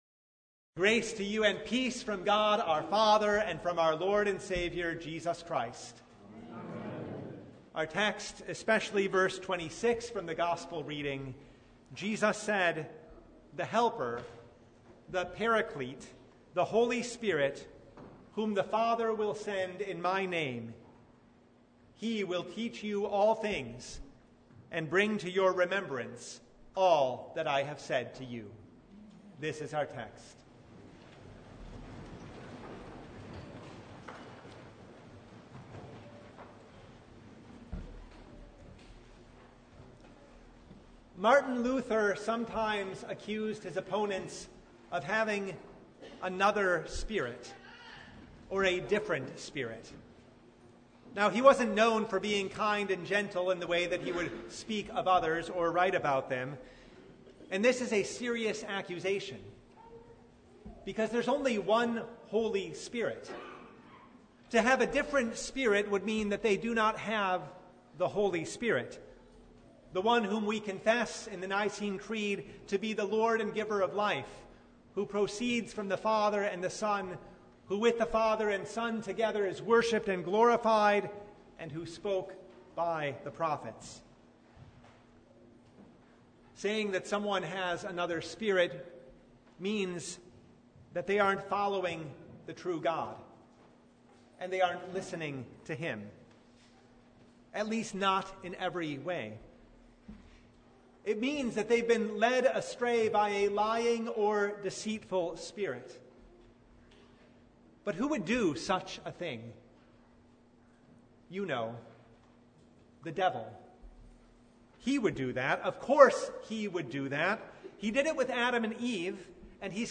Service Type: The Feast of Pentecost